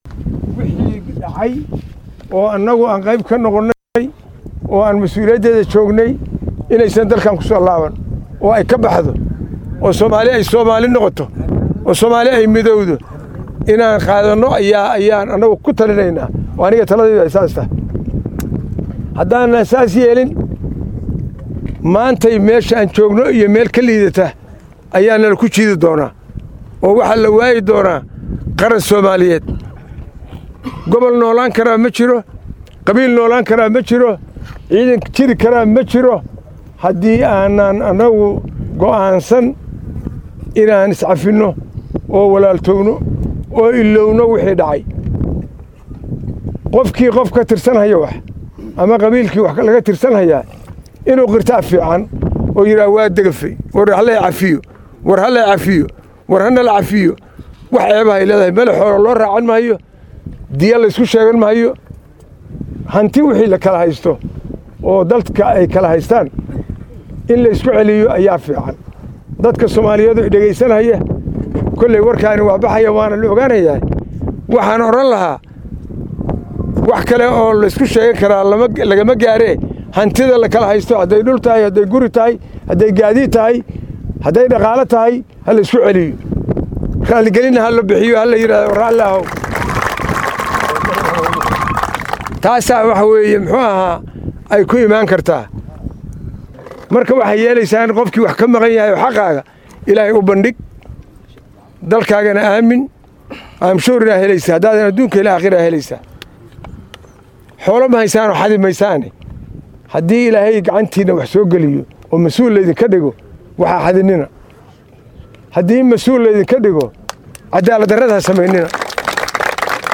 Taliyaha Ciidanka xoogga dalka oo la hadlay Ciidamadii uu booqday, ayaa kula dardaarmay  inay daacad u noqdaan shaqada Qaranka ay u hayaan, ayna la yimaadaan hab dhaqan wanaagsan, isagoo xusay in Ciidamadii uu booqday ay qeyb ka yihiin Barnaamijka isku dhafka Ciidamada qaranka oo dowladda Soomaaliya ay wado.
Dhagayso Taliyaha Ciidanka Xoogga dalka Soomaaliyeed